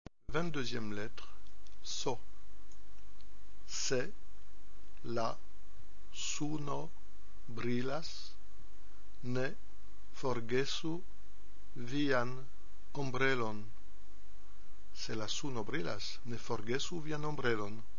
22 - S S
assis au soleil
-2) la phrase prononcée lentement en séparant bien les mots,
-3) la phrase prononcée normalement.